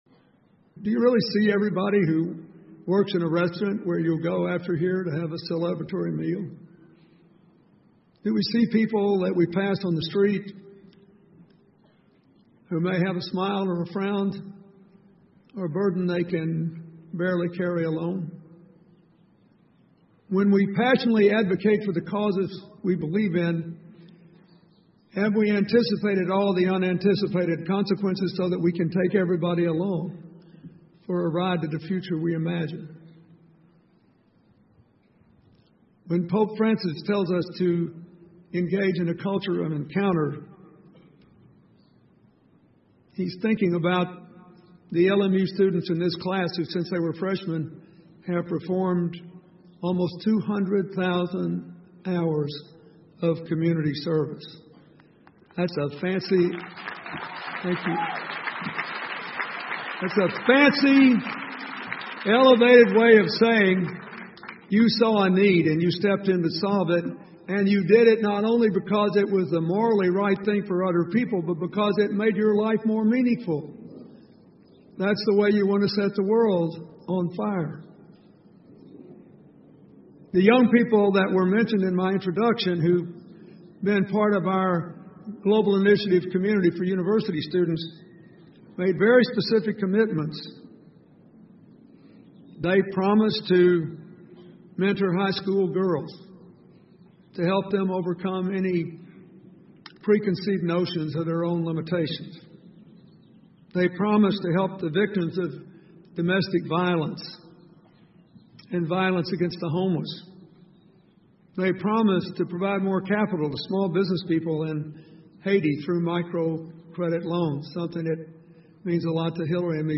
英文演讲录 比尔·克林顿：点燃世界(3) 听力文件下载—在线英语听力室